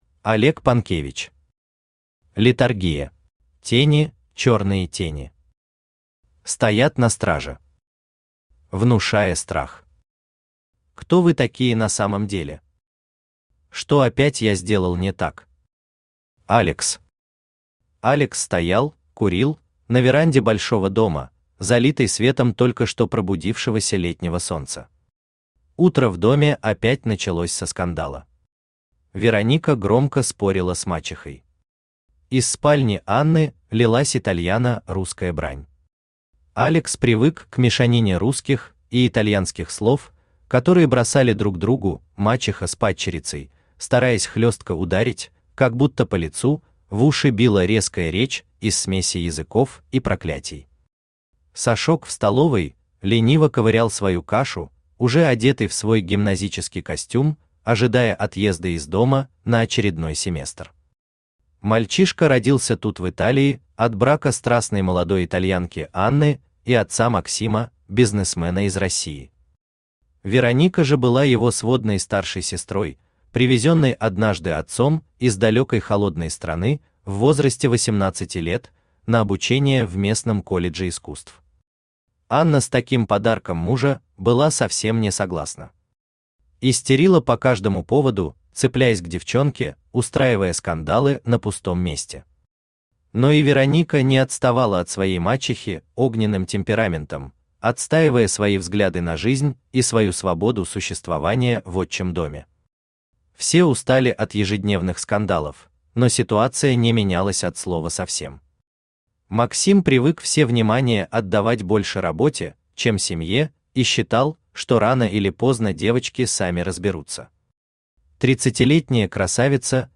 Aудиокнига Летаргия Автор Олег Панкевич Читает аудиокнигу Авточтец ЛитРес.